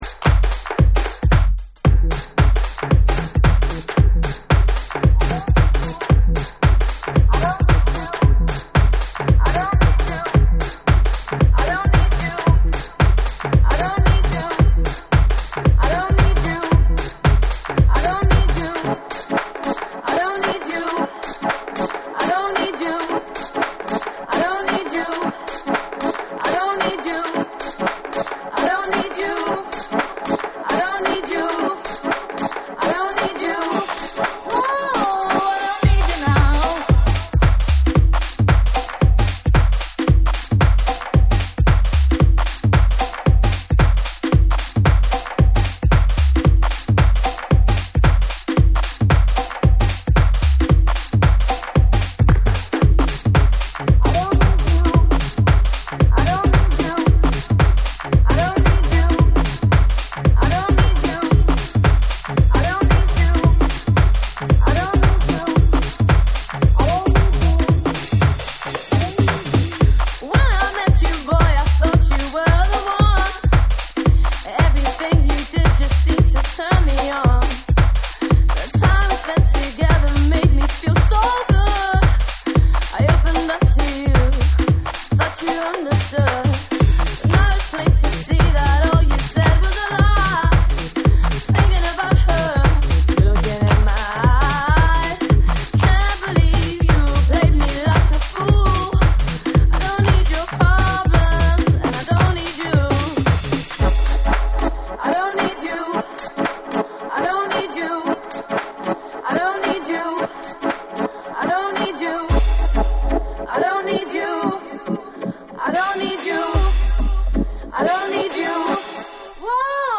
90'Sハウスとビートダウン・ハウスをクロスオーバーした
TECHNO & HOUSE